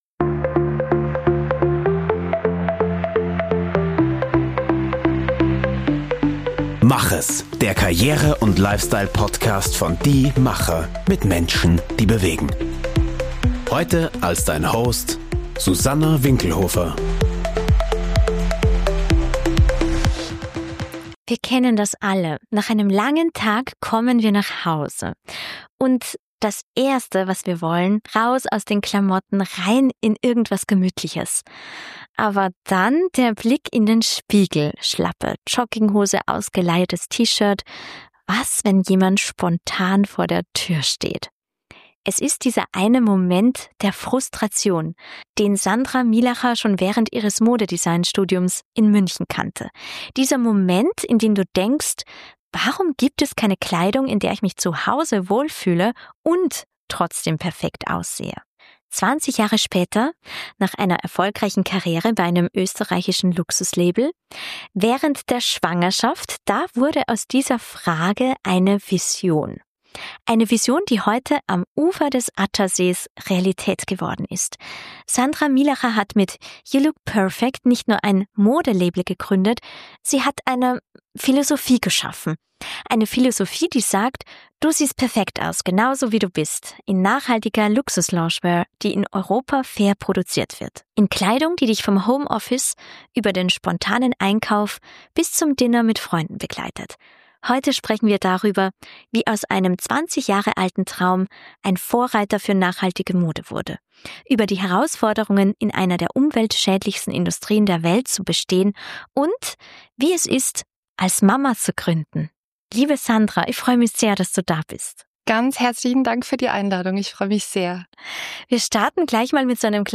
Ein Gespräch über Geduld, Mut und warum manchmal die einfachsten Ideen die revolutionärsten sind.